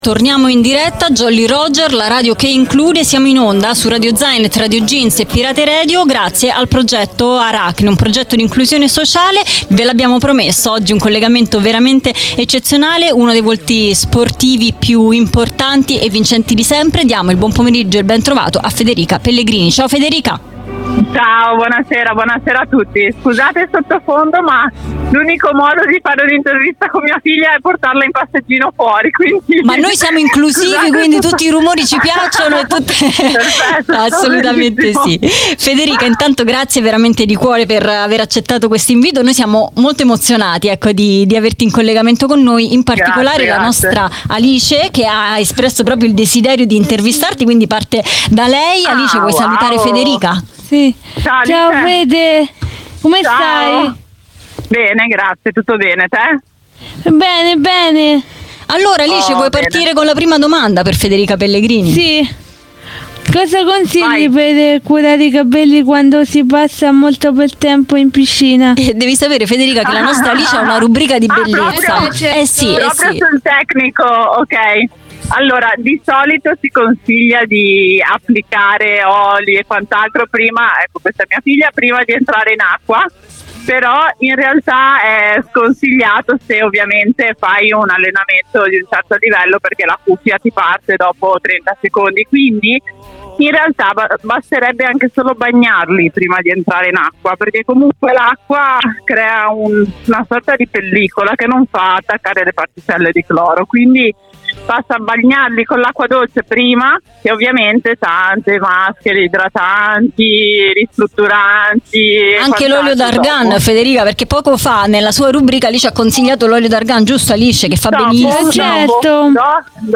Jolly Roger - Puntata 14 - Intervista a Federica Pellegrini